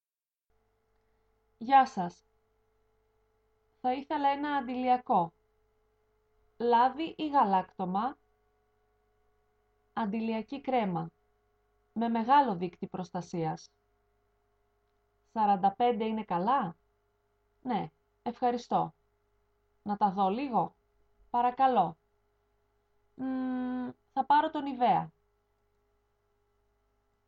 Dialog E: